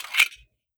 fps_project_1/45 ACP 1911 Pistol - Magazine Unload 003.wav at 804e4d68c3c9fcb827ee0ac68f3524d55a078dff